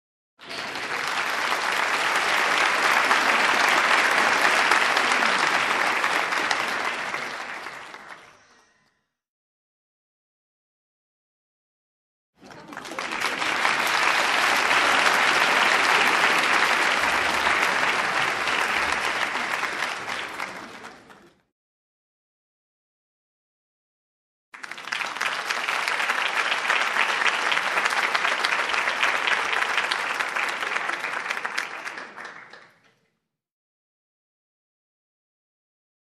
Звуки хлопков
Звук аплодисментов в театре — продолжительные хлопки